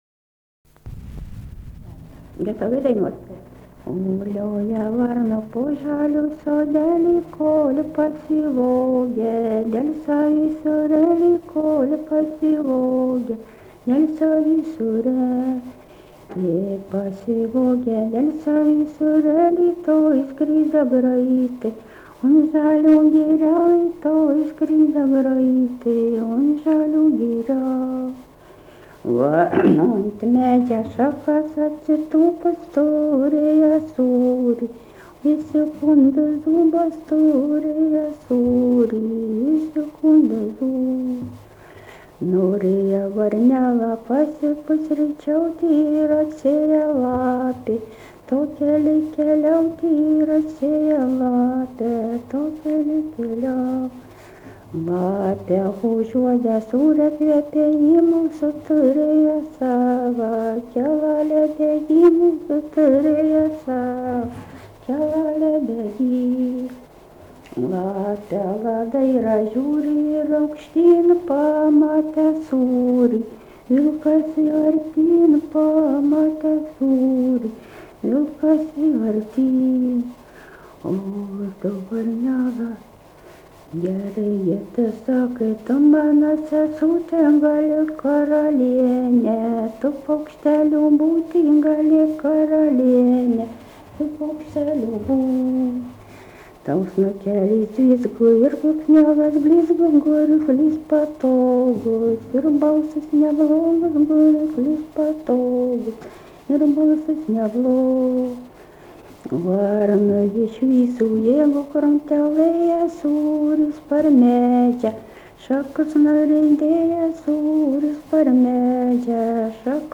daina
Vabalninkas
vokalinis